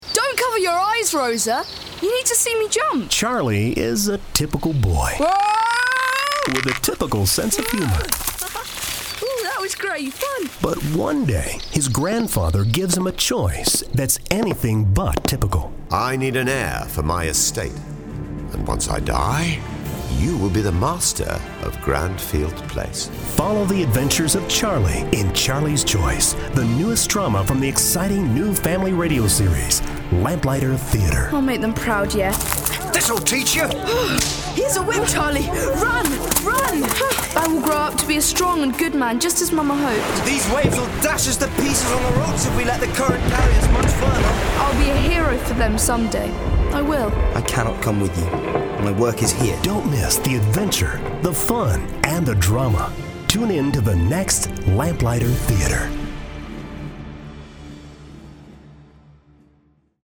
Charlie’s Choice – Dramatic Audio MP3 Download
Wonderful story line with wonderful sounds and voices.
They loved how there are different voices for the characters and sound effects.